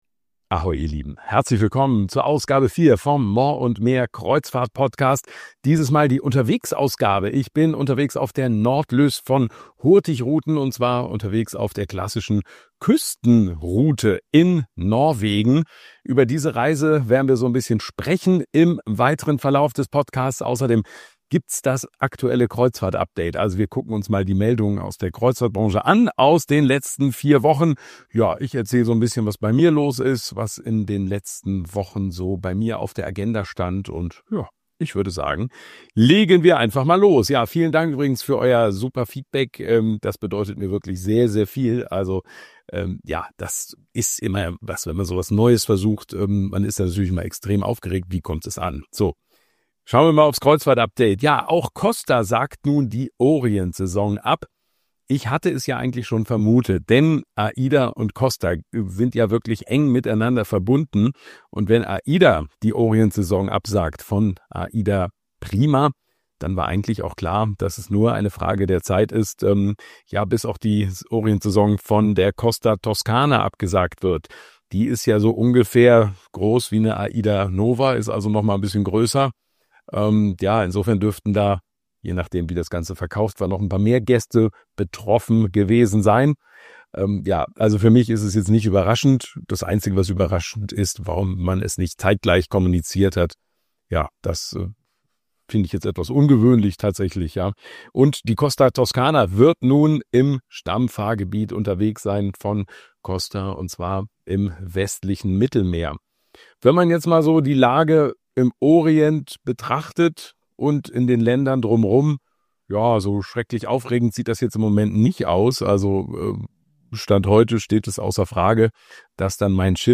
Ich melde imich von Bord der Nordlys auf der klassischen Küstenroute in Norwegen. Es gibt aktuelle Kreuzfahrt-News, persönliche Eindrücke von meiner ersten Hurtigruten-Reise und Einblicke in Themen, die die Branche bewegen.